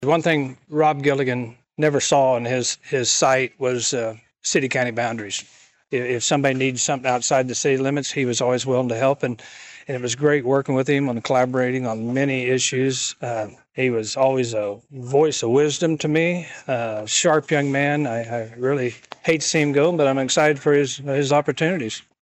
Colleagues, friends and community members all convened inside White Auditorium’s Little Theatre Wednesday to bid farewell to a longtime civil servant.
Among those in attendance was Lyon County Commissioner Scott Briggs.